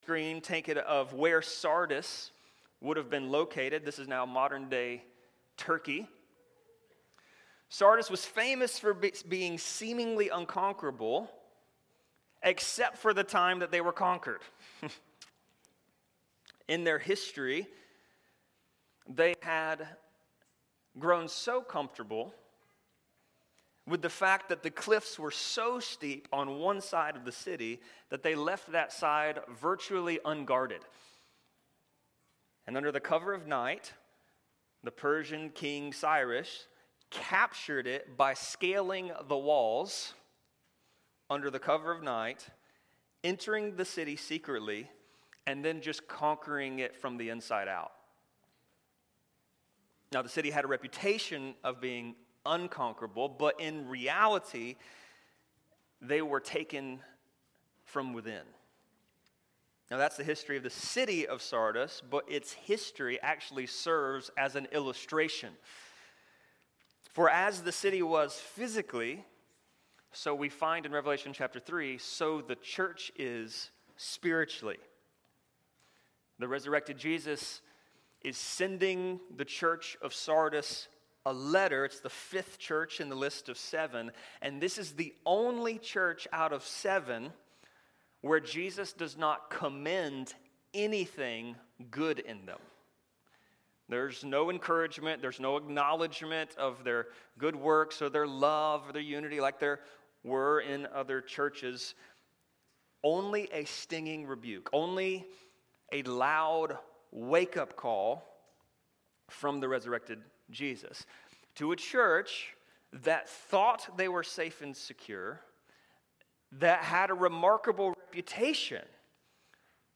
Sermons | St. Rose Community Church